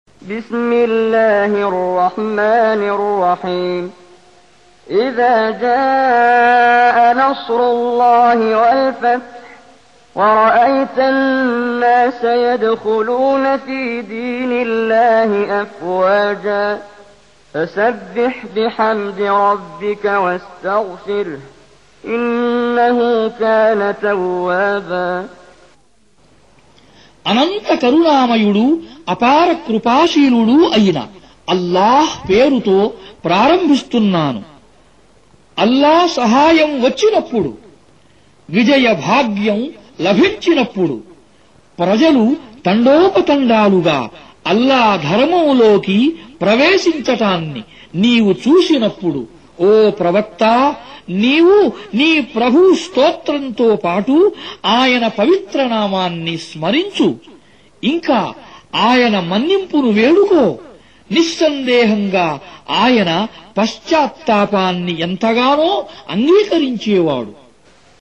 Surah Repeating تكرار السورة Download Surah حمّل السورة Reciting Mutarjamah Translation Audio for 110. Surah An-Nasr سورة النصر N.B *Surah Includes Al-Basmalah Reciters Sequents تتابع التلاوات Reciters Repeats تكرار التلاوات